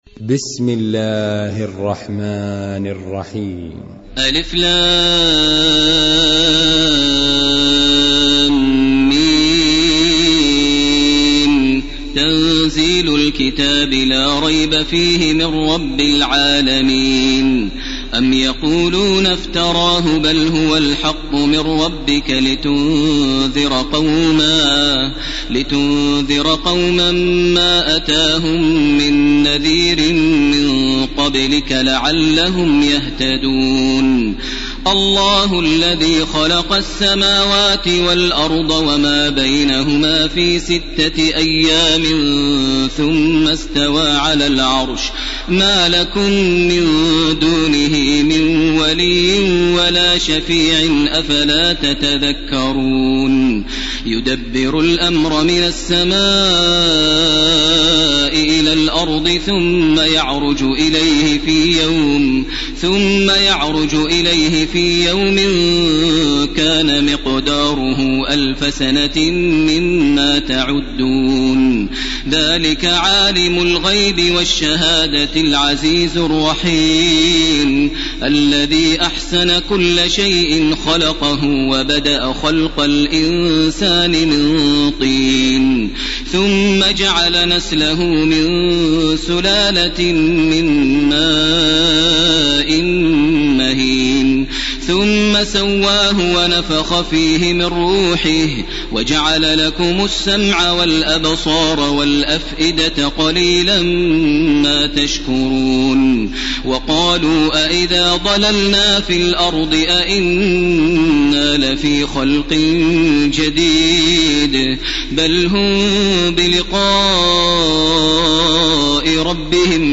تراويح ليلة 21 رمضان 1431هـ سورتي السجدة و الأحزاب Taraweeh 21 st night Ramadan 1431H from Surah As-Sajda and Al-Ahzaab > تراويح الحرم المكي عام 1431 🕋 > التراويح - تلاوات الحرمين